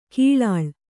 ♪ kīḷāḷ